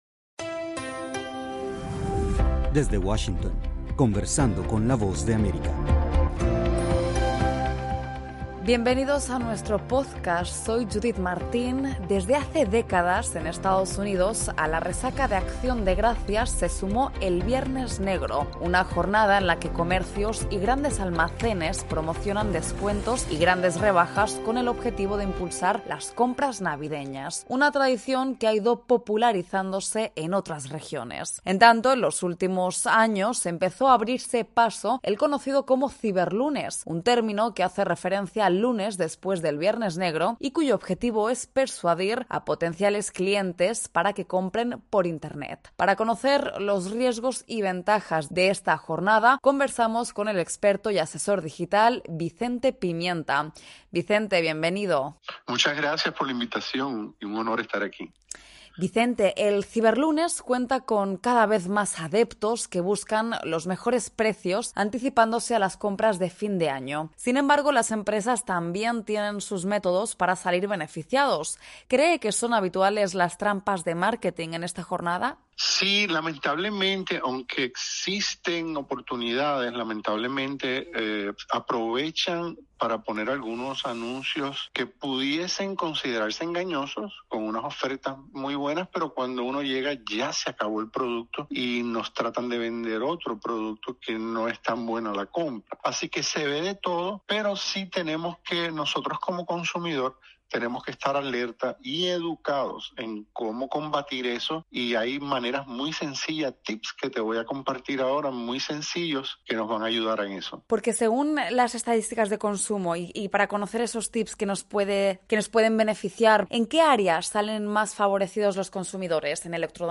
En los últimos años las compras online han ganado terreno y el ciberlunes se ha popularizado en EEUU y en otras regiones suponiendo el pistoletazo de salida a las compras navideñas. Para analizar las ventajas y trampas de esta jornada, conversamos con el asesor y experto digital